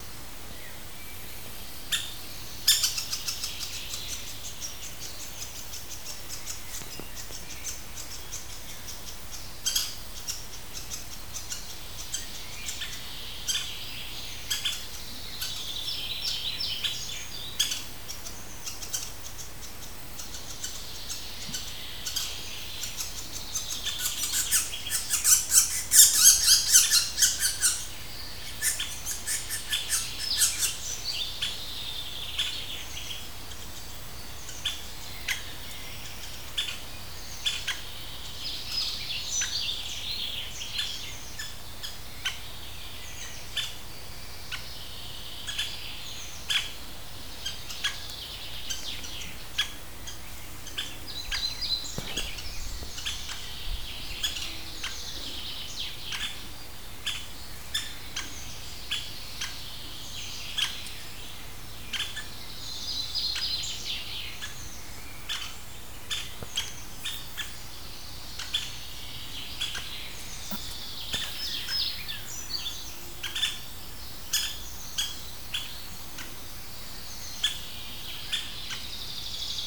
белоспинный дятел, Dendrocopos leucotos
СтатусBaro mazuļus (JB)